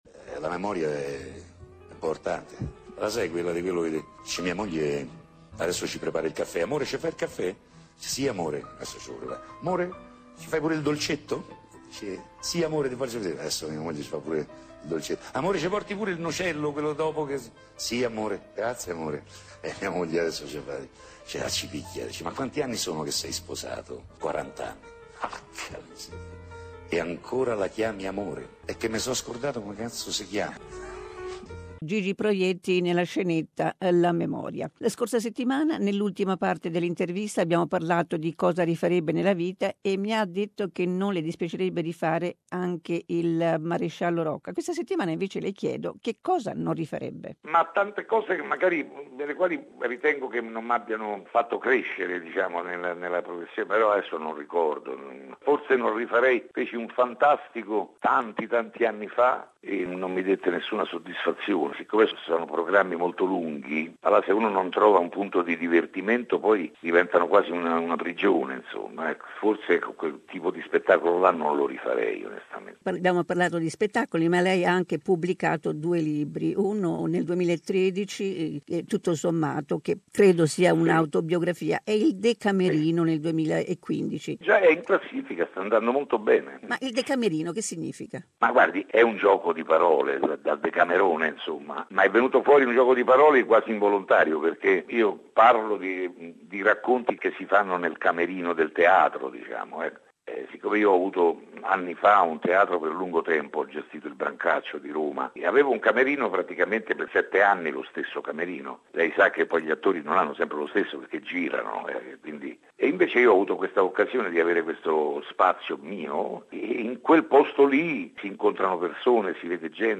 Second part of an interview with popular Italian actor, director, dubber and singer Gigi Proietti.